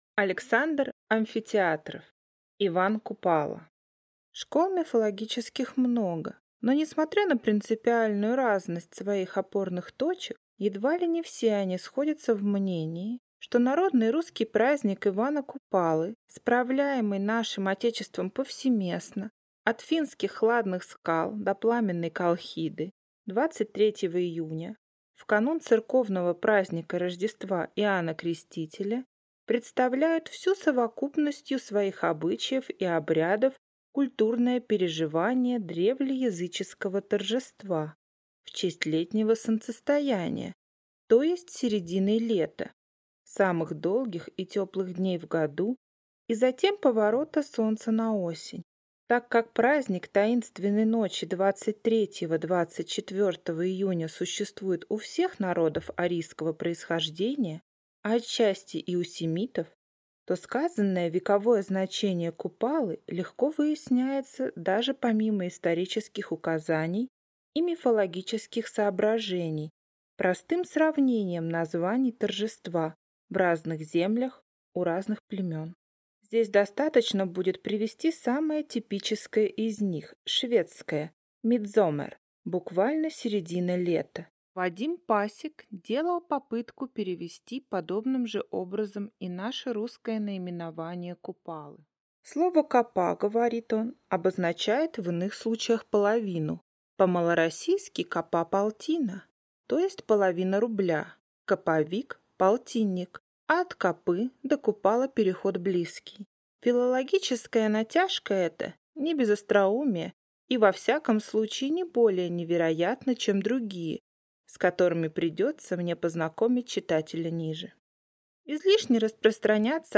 Аудиокнига Иван Купало | Библиотека аудиокниг